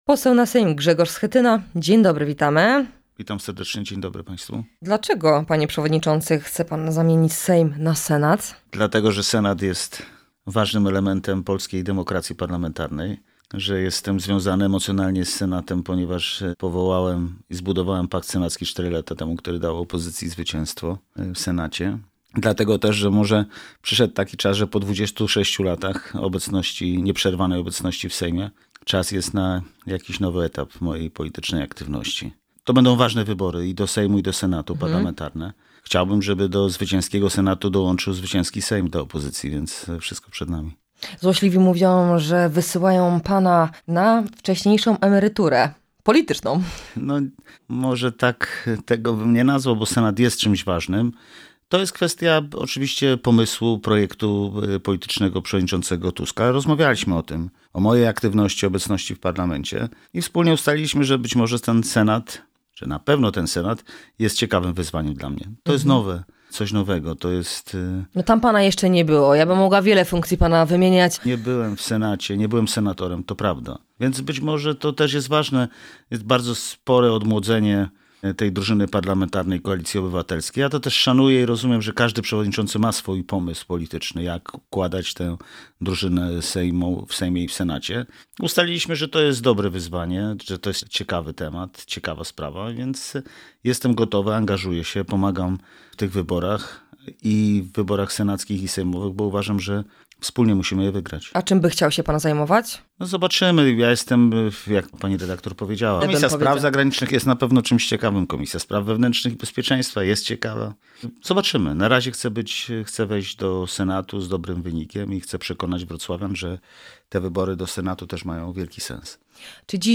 Poseł Grzegorz Schetyna będzie walczył w najbliższych wyborach Senat w okręgu nr 7. W audycji „Poranny Gość” pytamy go o jego start, o aferę wizową i sytuację w piłkarskim Śląsku Wrocławskim.